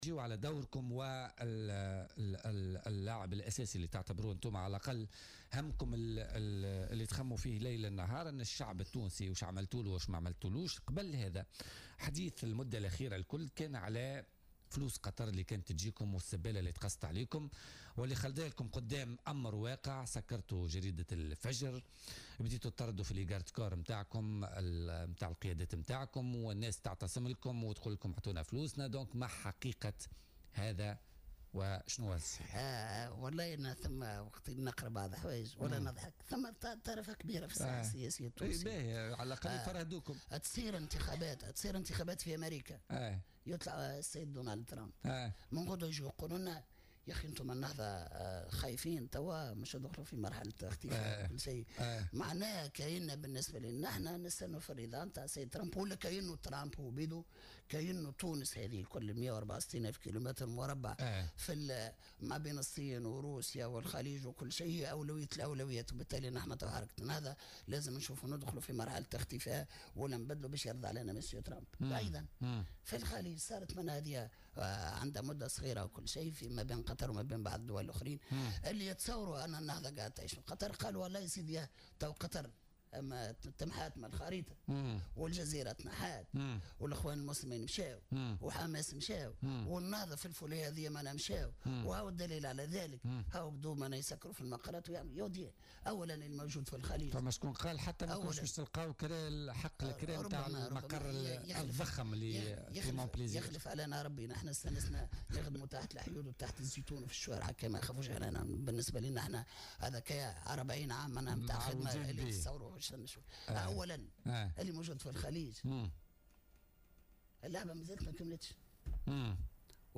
رد القيادي في حركة النهضة عبد الحميد الجلاصي ضيف بولتيكا اليوم الخميس 6 جويلية 2017 على الاتهامات التي طالت الحركة بتلقيها تمويلات قطرية.